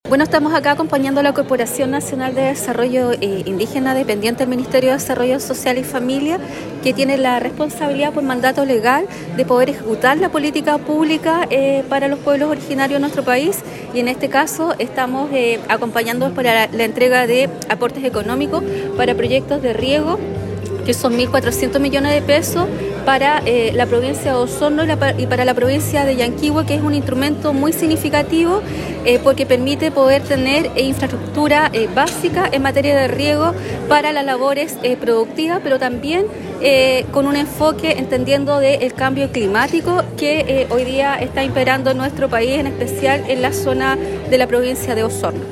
La Delegada Presidencial Provincial, Claudia Pailalef destacó la relevancia en la entrega de aportes económicos para proyectos de riego, que son 1.400 millones de pesos para la provincia de Osorno y para la provincia de Llanquihue.